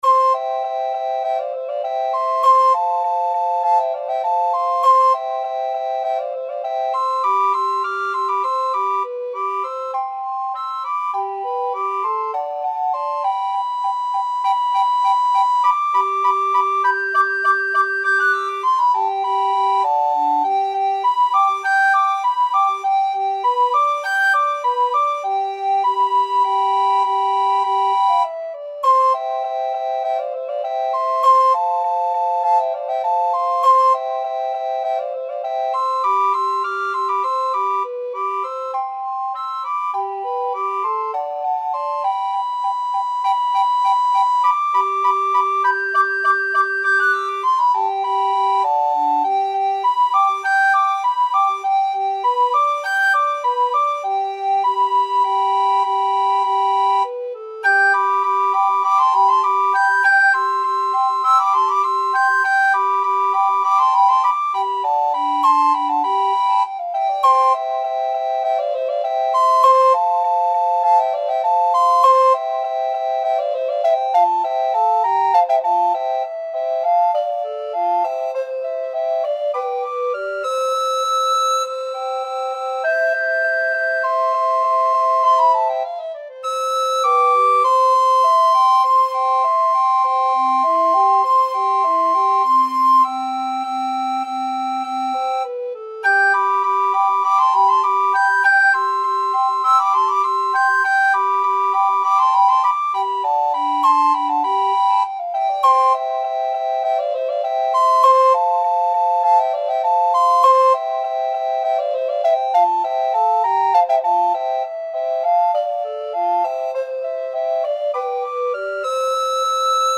Free Sheet music for Recorder Trio
Soprano RecorderAlto RecorderTenor Recorder
2/4 (View more 2/4 Music)
~ = 100 Allegretto
C major (Sounding Pitch) (View more C major Music for Recorder Trio )
Classical (View more Classical Recorder Trio Music)